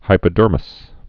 (hīpə-dûrmĭs) also hy·po·derm (hīpə-dûrm)